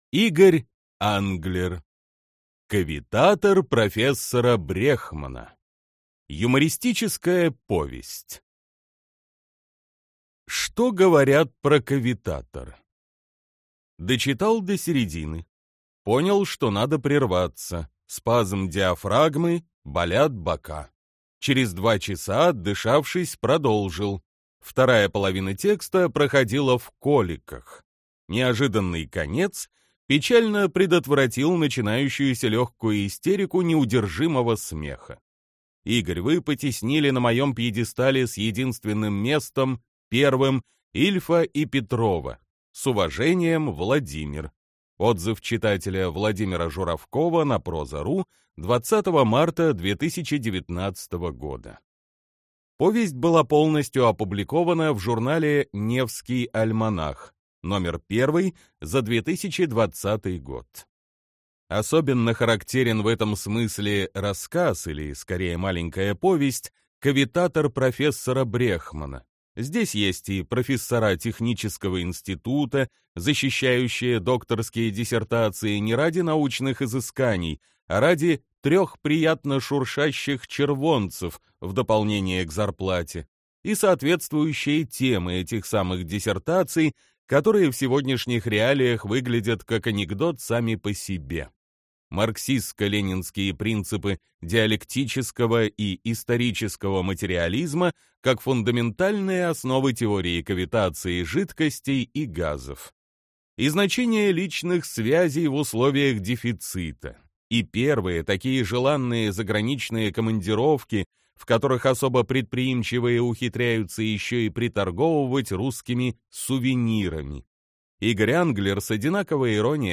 Аудиокнига Кавитатор профессора Брехмана. Юмористическая повесть | Библиотека аудиокниг